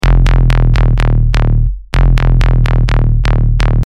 Rumble: Bass Collection
Earth-Shaking Basslines & Groovey Basslines for Dancefloor Ready Tunes.
BASS_-_Mercy.mp3